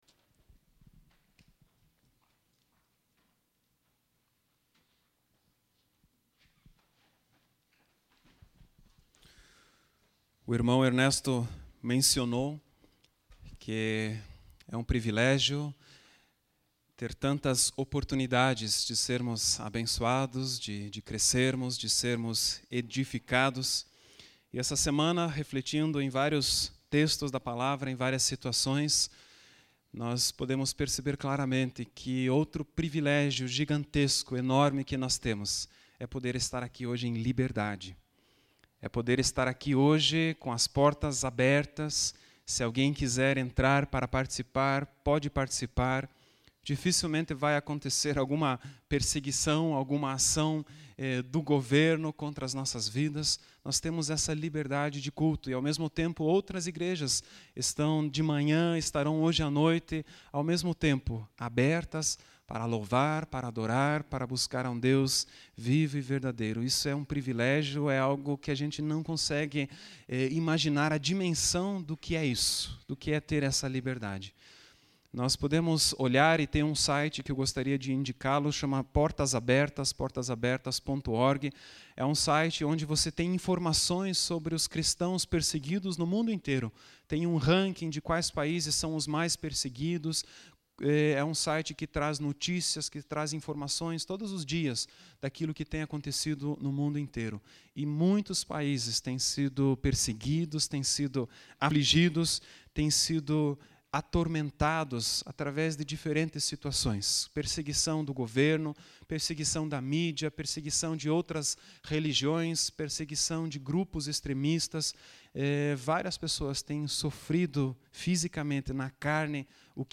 Palavra ministrada no domingo